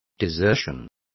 Complete with pronunciation of the translation of desertion.